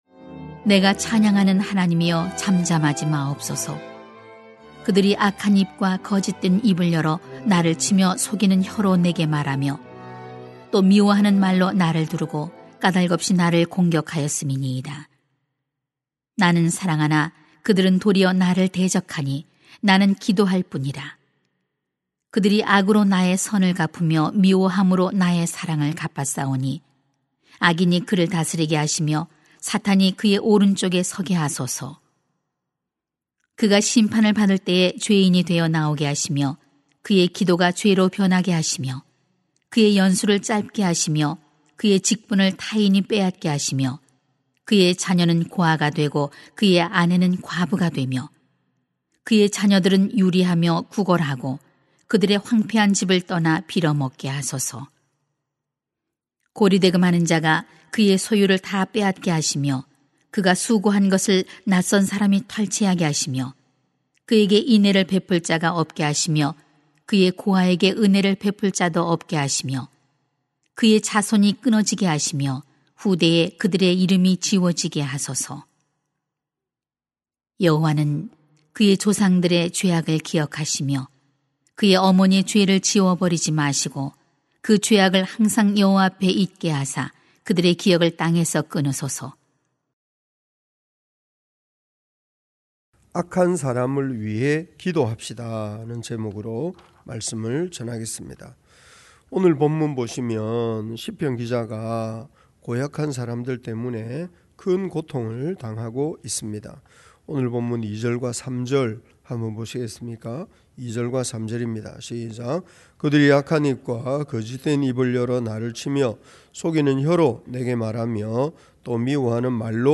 [시 109:1-15] 악한 사람을 위해 기도합시다 > 새벽기도회 | 전주제자교회